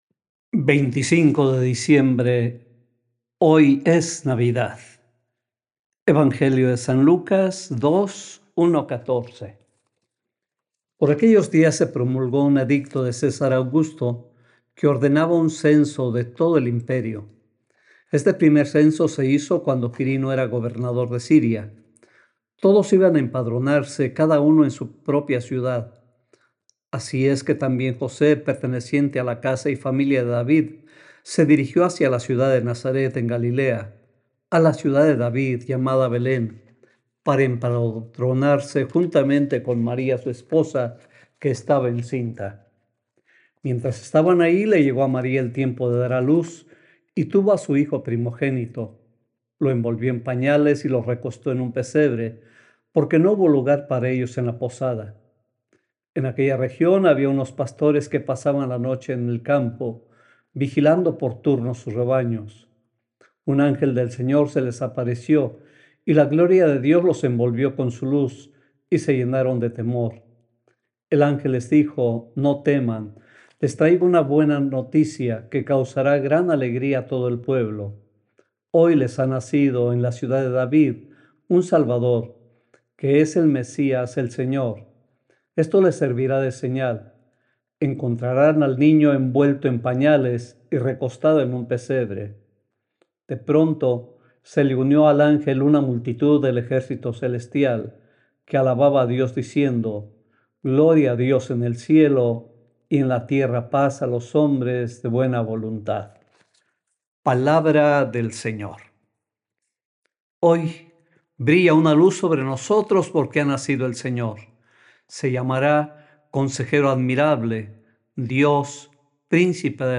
Reflexión de Monseñor Enrique Díaz: “Hoy nos ha nacido el Salvador”